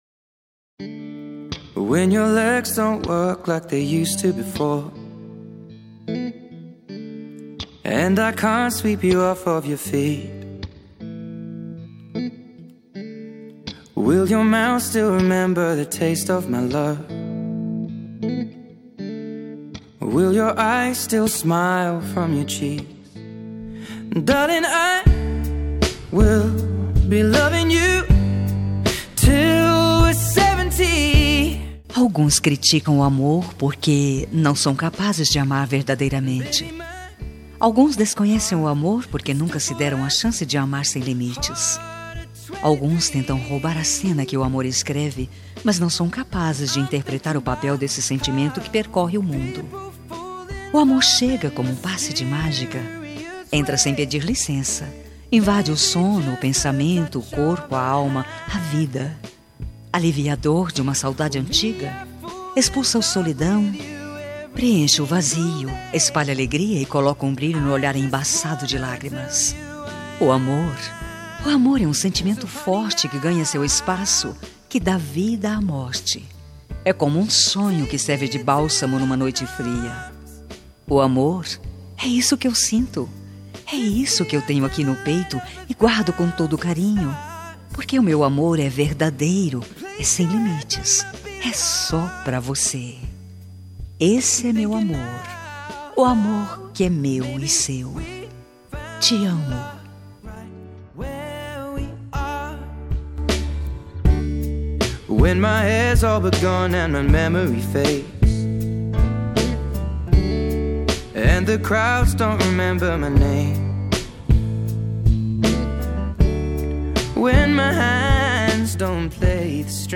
Telemensagem Romântica GLS – Voz Feminina – Cód: 5479